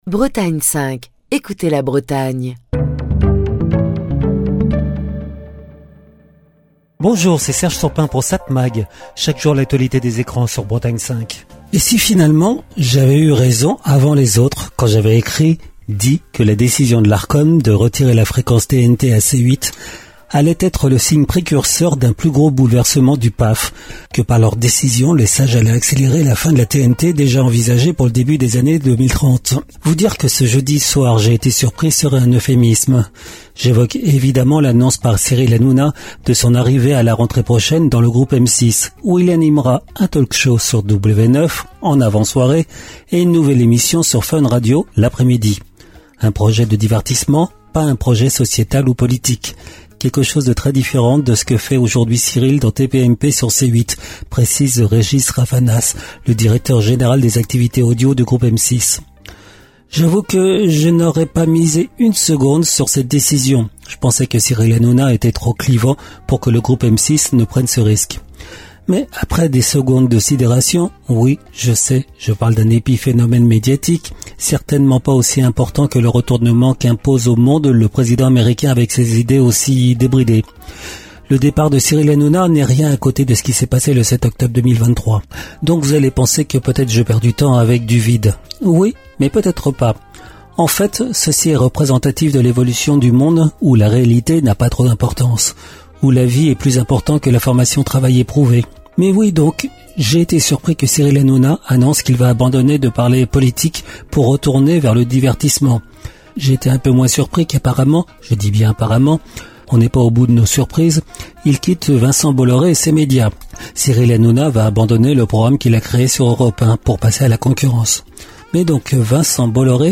Chronique du 28 février 2025.